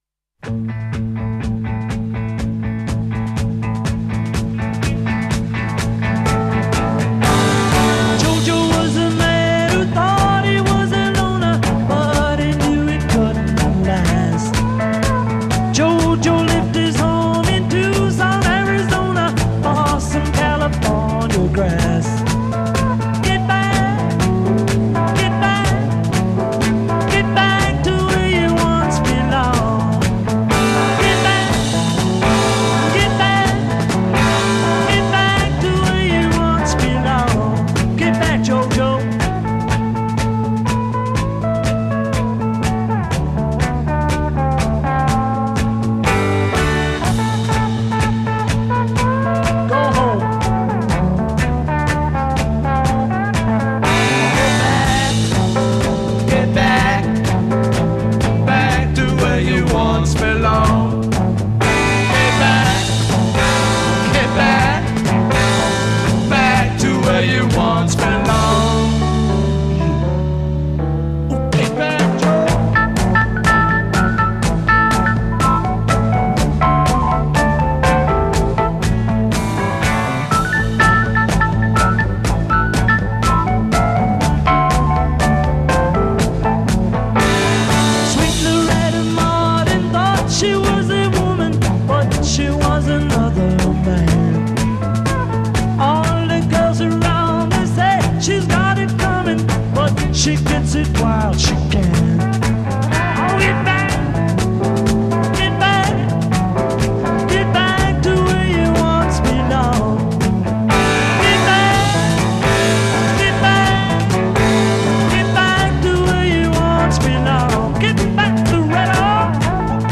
Intro 8 Gradual crescendo on accompanying rhythm.
Verse 16 Guitar solo
Chorus 18 Two-part harmonies on hook. b
Verse 16 Piano solo